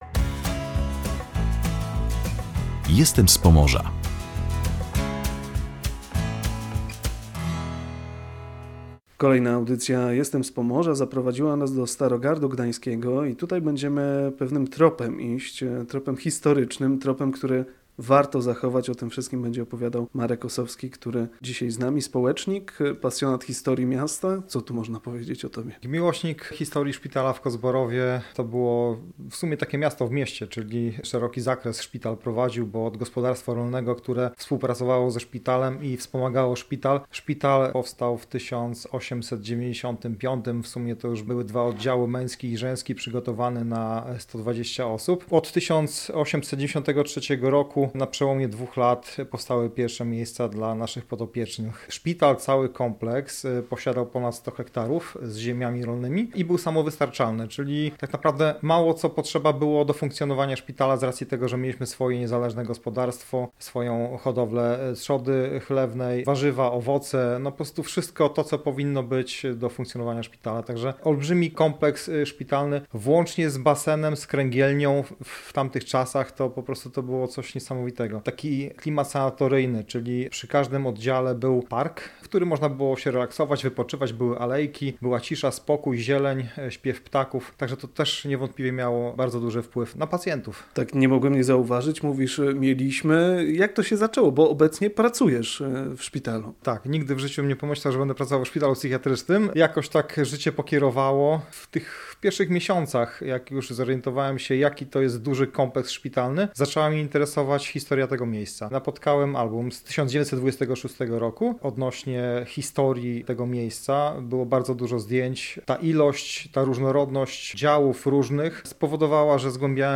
Nasz rozmówca opowiada o artefaktach związanych ze szpitalem i zachowywaniu pamięci.